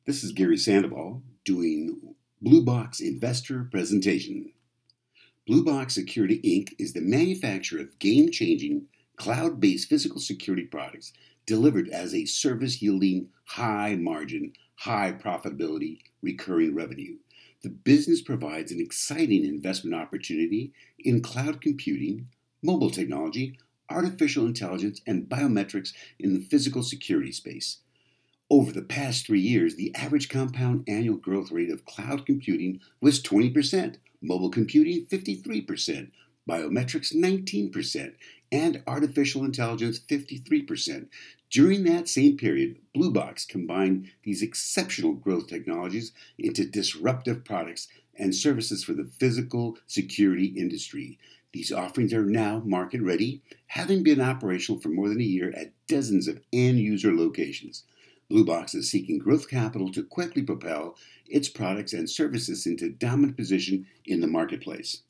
Industrial